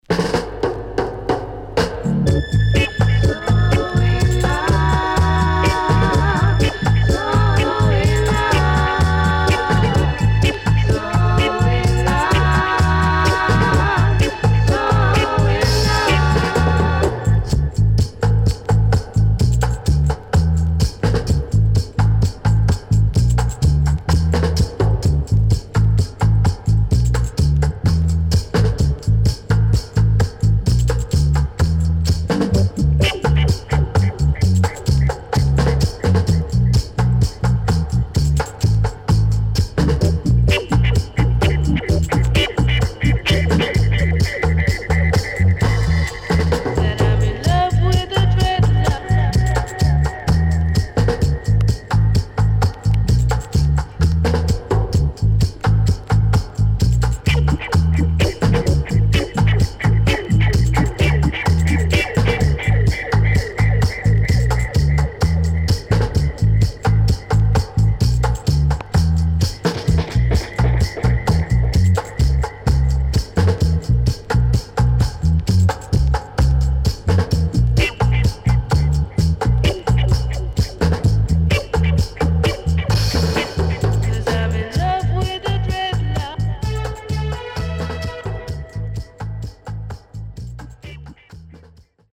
Great Conscious Lovers & Dubwise.Good Condition
SIDE A:少しチリノイズ入りますが良好です。